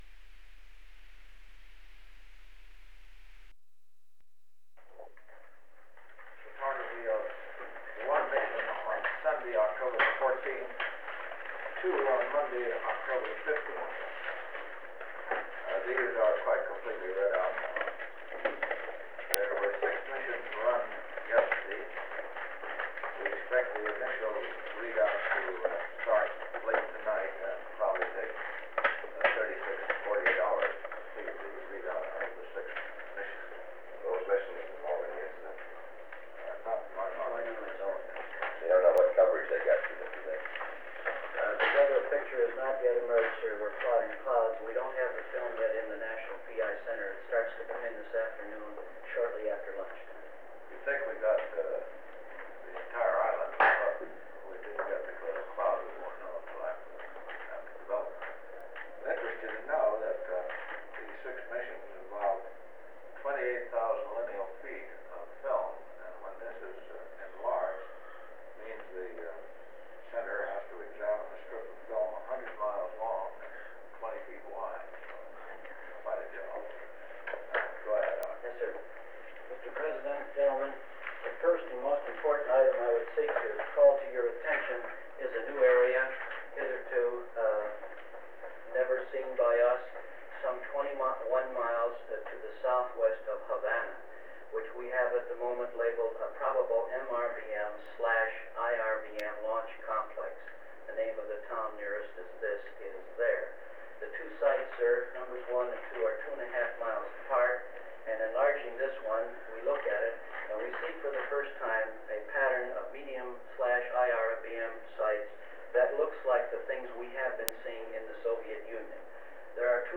Secret White House Tapes | John F. Kennedy Presidency Meeting on the Cuban Missile Crisis Rewind 10 seconds Play/Pause Fast-forward 10 seconds 0:00 Download audio Previous Meetings: Tape 121/A57.